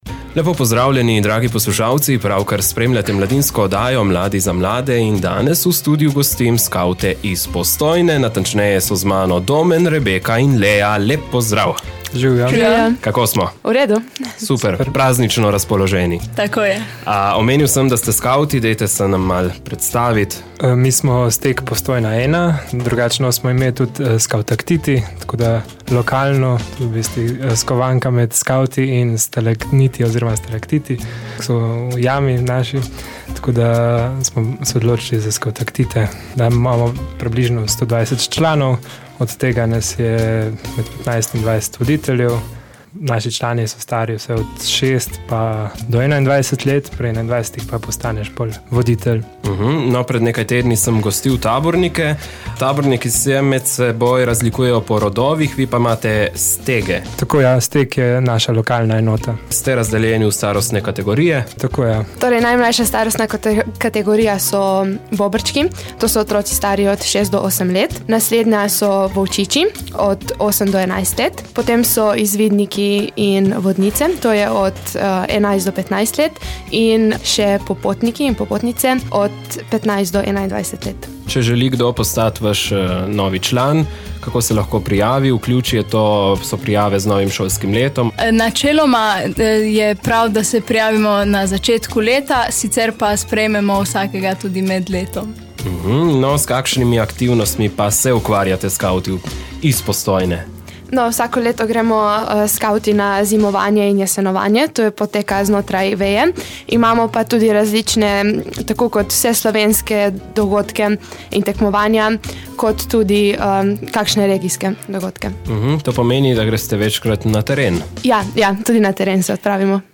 Jutri bodo po celotni Sloveniji skavti razdelili Luč miru. Kako poteka prenos ognja, s kakšnimi aktivnostimi se še ukvarjajo in kaj načrtujejo v letu 2019, smo povprašali skavte iz Postojne.